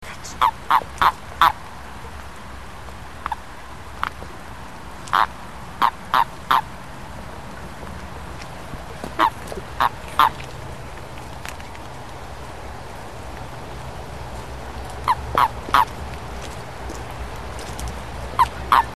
bufo_bufo1.mp3